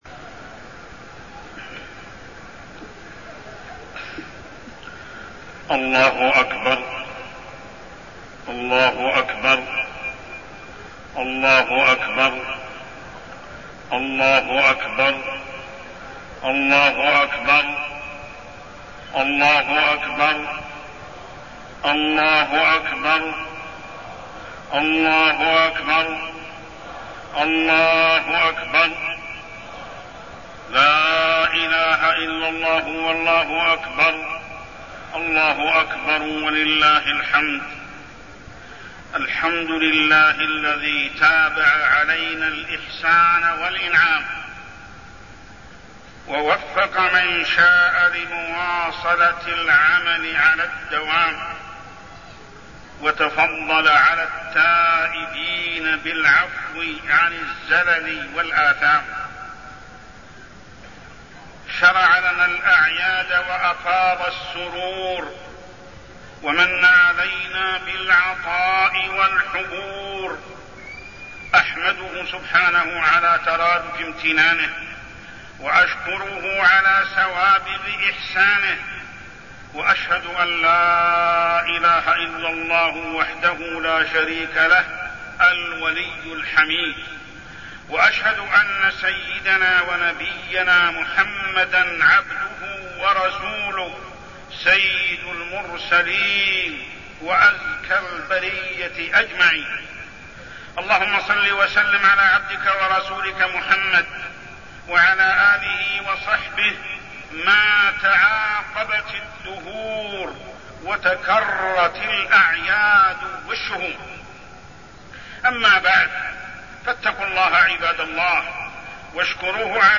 خطبة عيد الفطر -نصائح عامة
تاريخ النشر ١ شوال ١٤١٥ هـ المكان: المسجد الحرام الشيخ: محمد بن عبد الله السبيل محمد بن عبد الله السبيل خطبة عيد الفطر -نصائح عامة